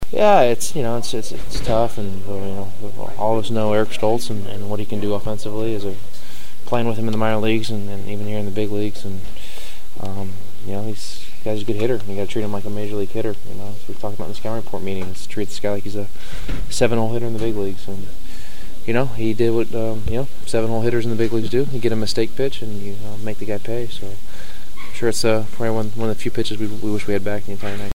aj-ellis-comments-on-stults-homerun.mp3